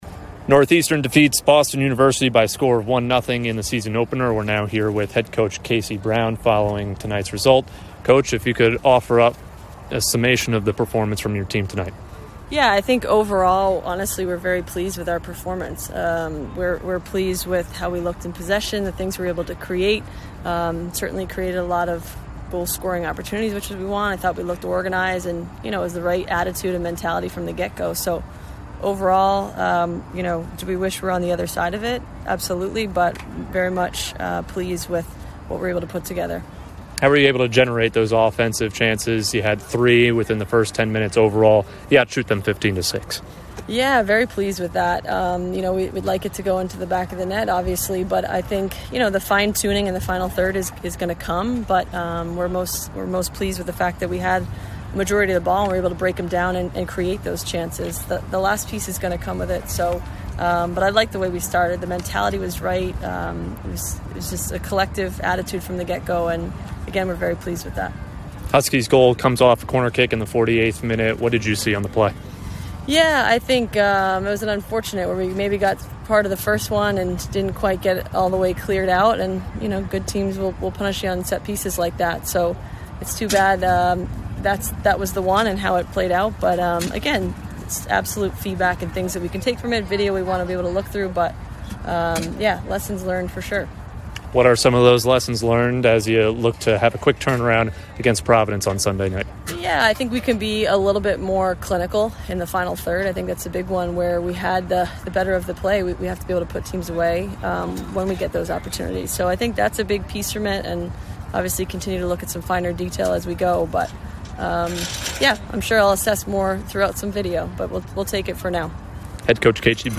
Northeastern Postgame Interview